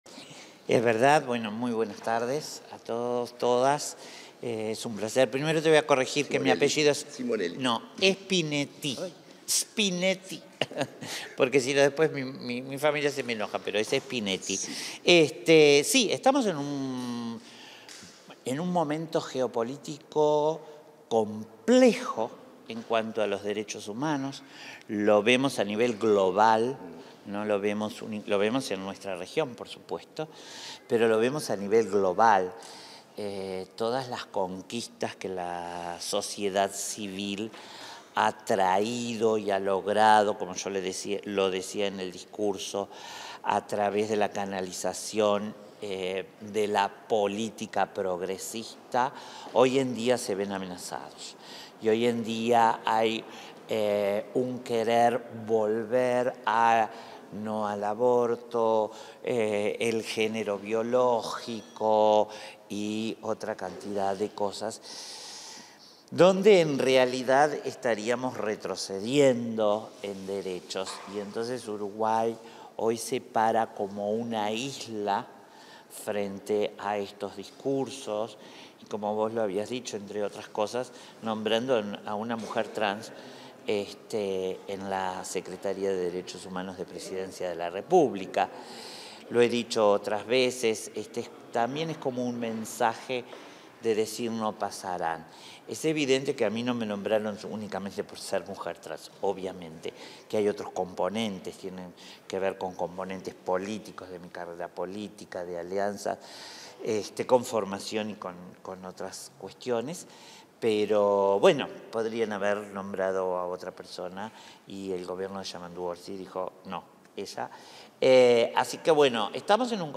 Declaraciones de la secretaria de Derechos Humanos, Collette Spinetti